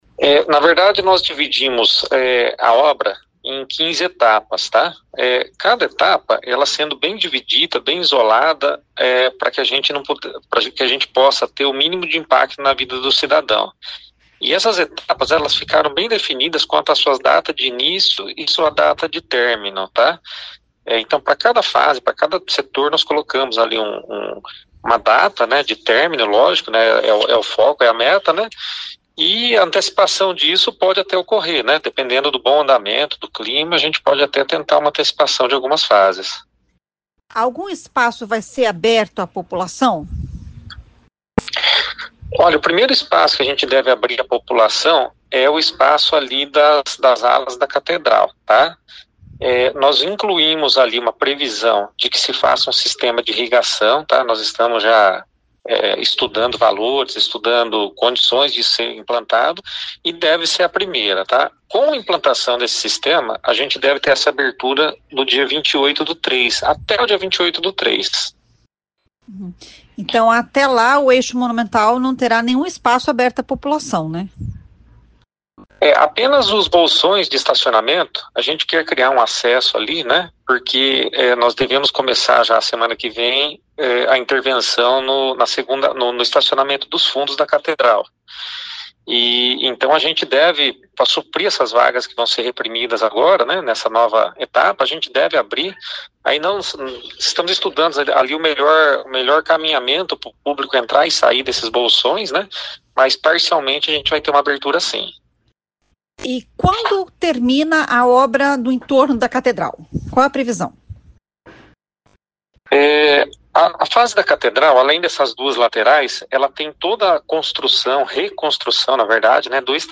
Ouça o que diz o secretário de Obras Arthur Tunes: